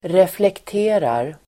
Uttal: [reflekt'e:rar]